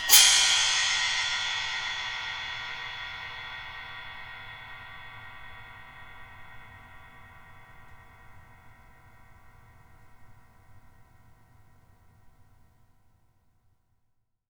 Index of /90_sSampleCDs/Sampleheads - New York City Drumworks VOL-1/Partition A/KD RIDES
ZING      -L.wav